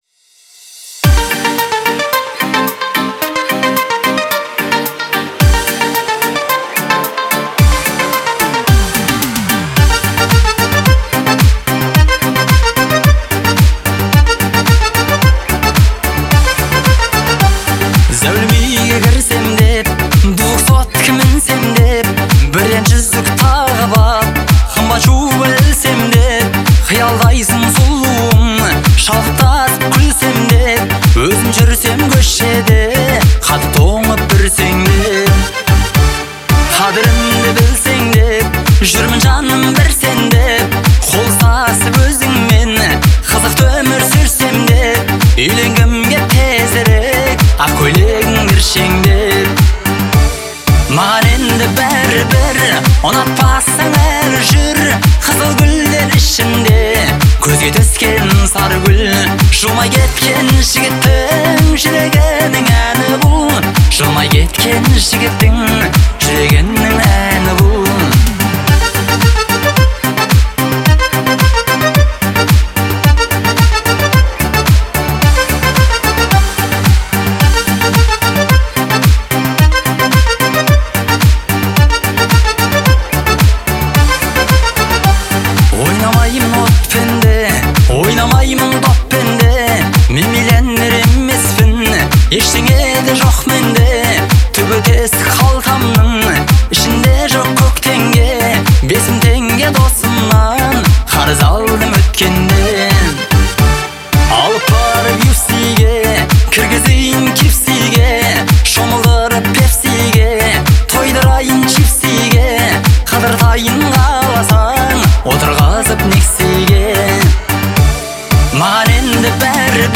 Особенностью звучания является мелодичный вокал